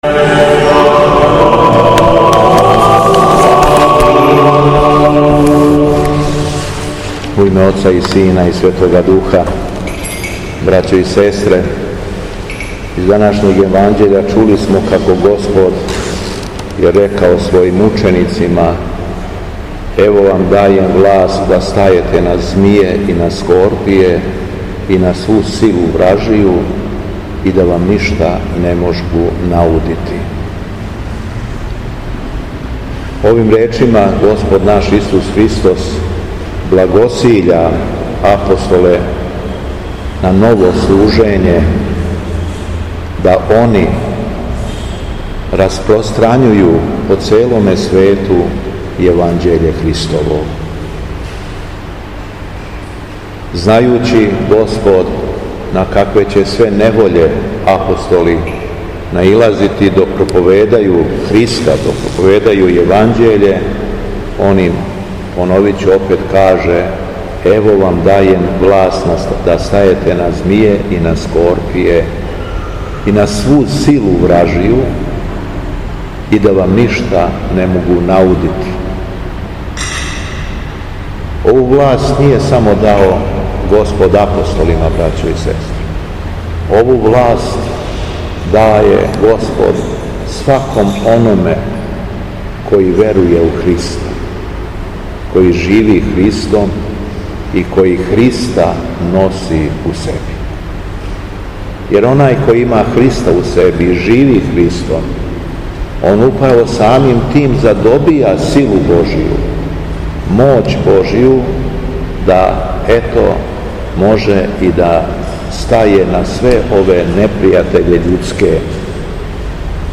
У понедељак, 28. јула 2025. године, када наша света Црква прославља свете мученике Кириака и Јулиту, Његово Високопреосвештенство Митрополит шумадијски Господин Јован, служио је свету архијерејску литургију у храму Светога Саве у крагујевачком насељу Аеродром.
Беседа Његовог Високопреосвештенства Митрополита шумадијског г. Јована
По прочитаном Јеванђељу Високопреосвећени обратио се верном народу надахнутом беседом: